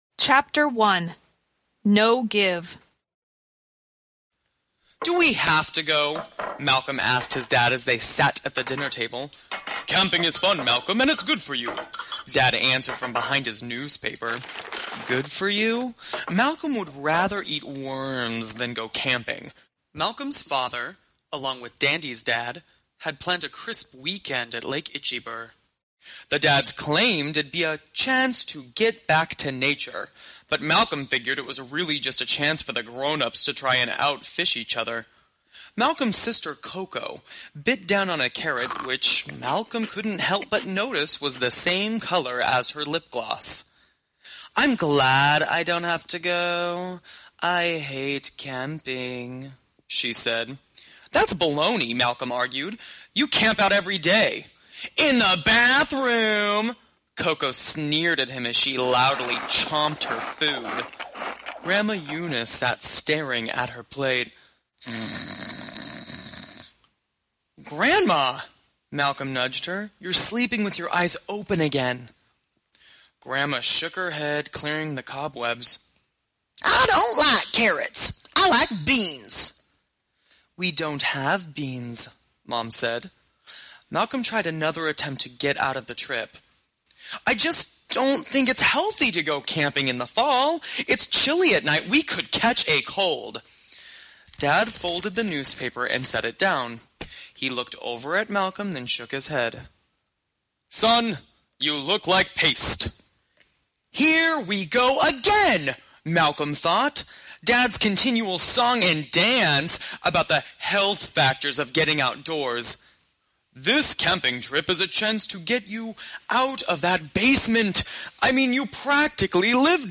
Unabridged — null54 minutes
Audiobook